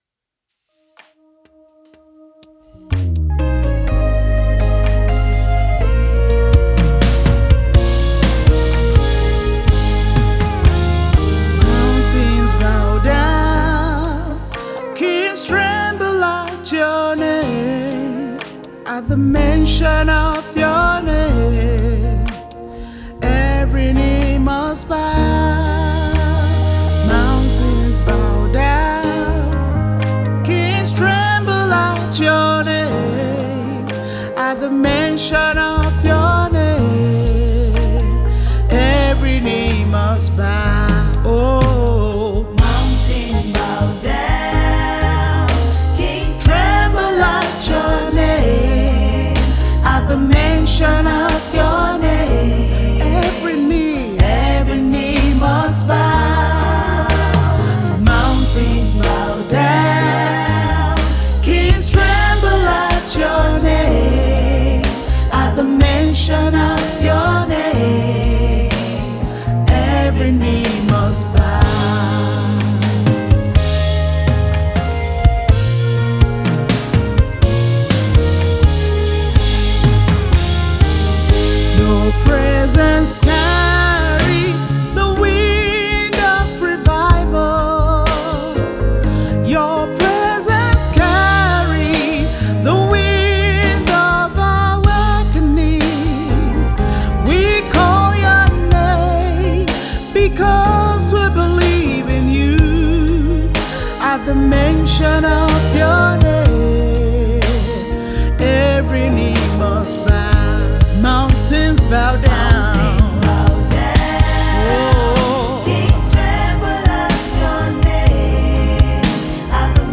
Songwriter and a performing Gospel artist